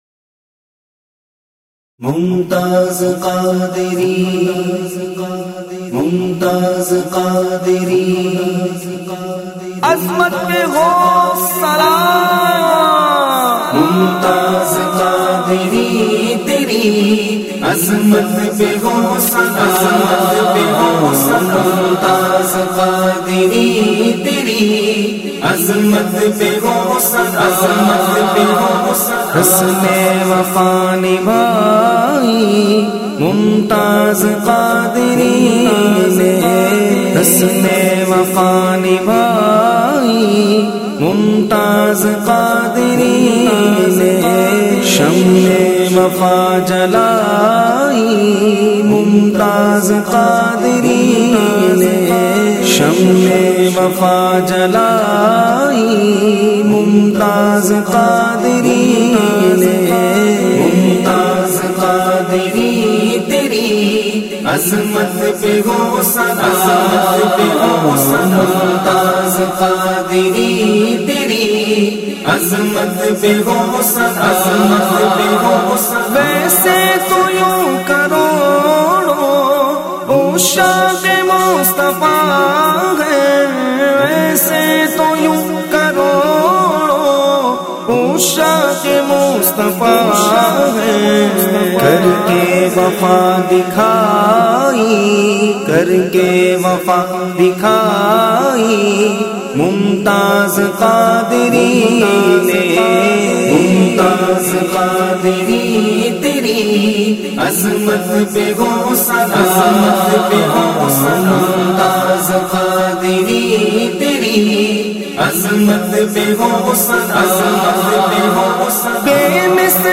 کلام